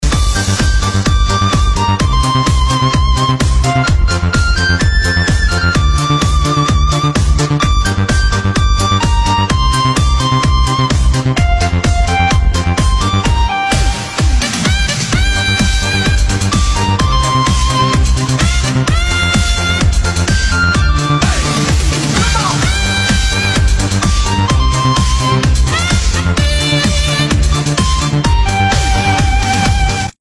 громкие
веселые
без слов
инструментальные
духовые